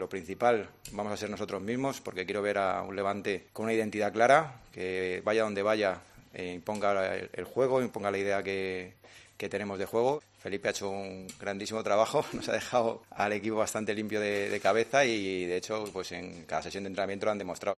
“Siempre que hablo de los rivales me veréis decir que no será fácil sea quien sea y será difícil y complicado pero lo principal es nosotros mismos. Yo quiero ver a un Levante que vaya donde vaya imponga el juego e imponga la idea que tenemos, que dependa poco del rival”, indicó el entrenador madrileño en la rueda de prensa previa al partido.